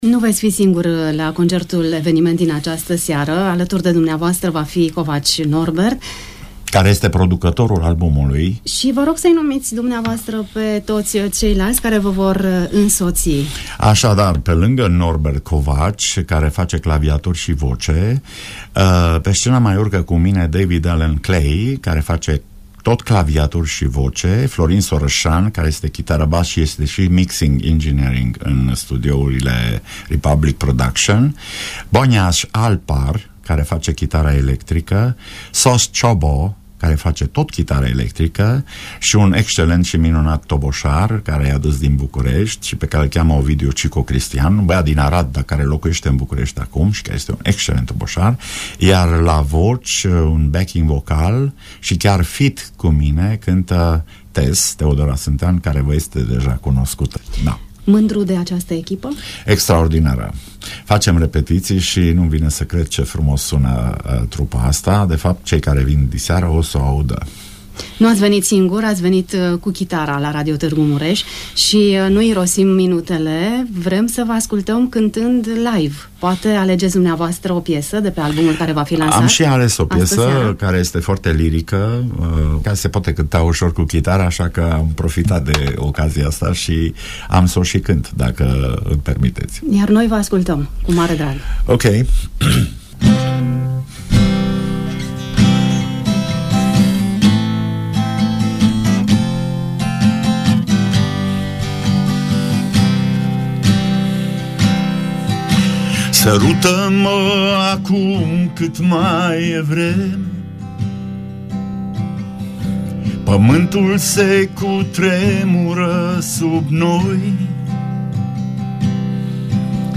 invitat la Pulsul zilei.